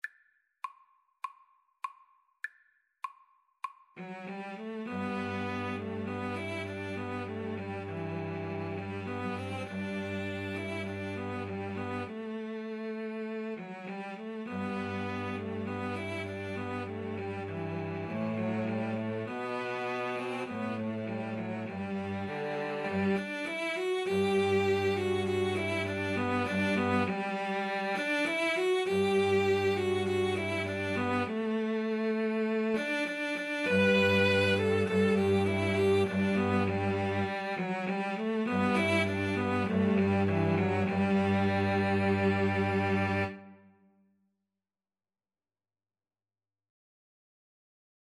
G major (Sounding Pitch) (View more G major Music for Cello Trio )
Espressivo
Cello Trio  (View more Intermediate Cello Trio Music)